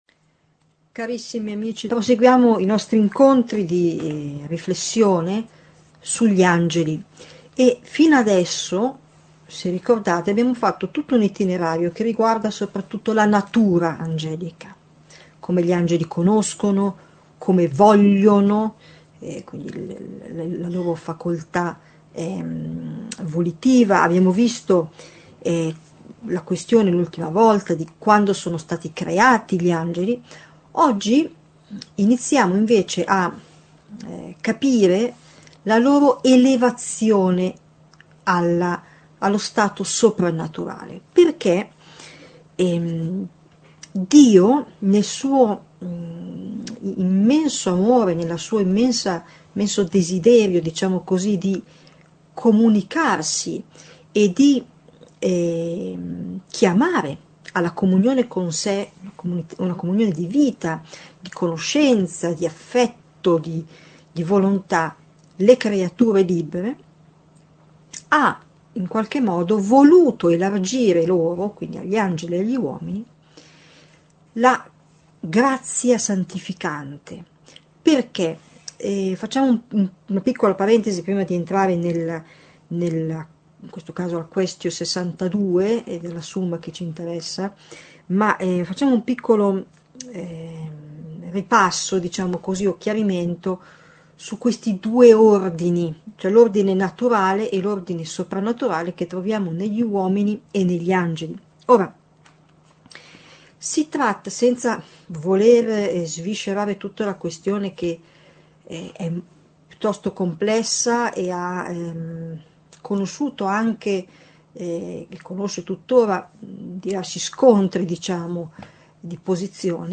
Catechesi adulti